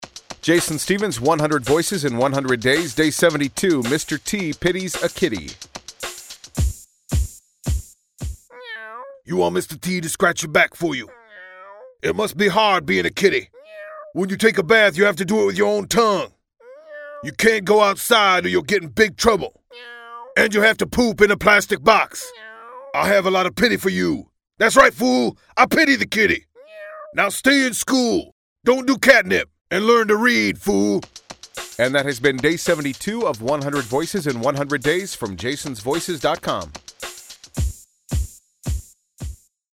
Tags: celebrity sound alike, Mr. T impression, voice matching, voice over